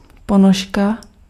Ääntäminen
GenAm: IPA : /sɑk/ US : IPA : [sɑk] RP : IPA : /sɒk/